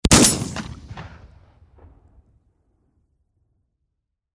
Одиночный выстрел из дробовика на открытом пространстве